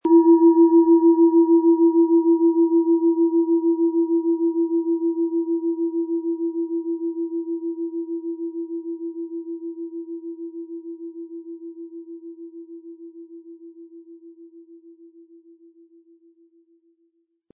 Hopi Herzton
• Tiefster Ton: Mond
Wir haben versucht den Ton so authentisch wie machbar hörbar zu machen, damit Sie hören können, wie die Klangschale bei Ihnen klingen wird.
Ein die Schale gut klingend lassender Schlegel liegt kostenfrei bei, er lässt die Planetenklangschale Hopi-Herzton harmonisch und angenehm ertönen.
MaterialBronze